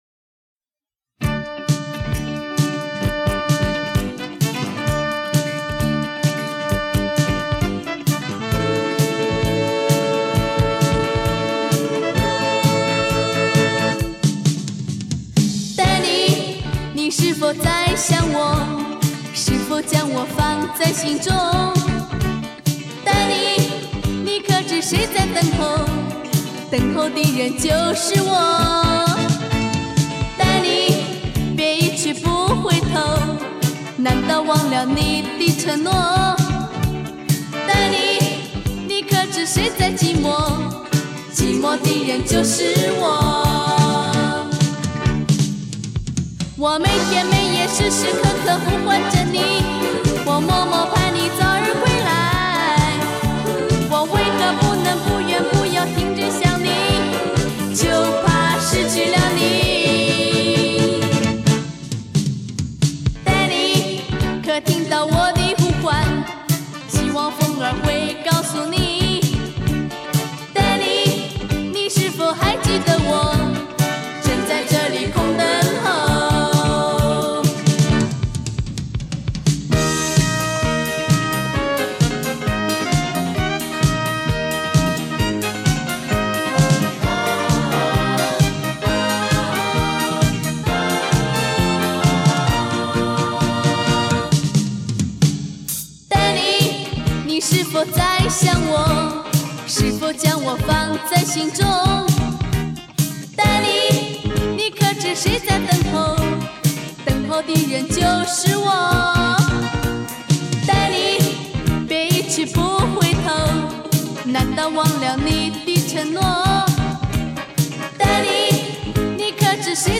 22年前听这盘磁带，歌词朗朗上口，旋律轻盈。
这张专辑的制作或许放在今天的技术水准来讲，应该是比较粗糙的，而正是这种少加修饰的味道，会让听者有完全不一样的体会。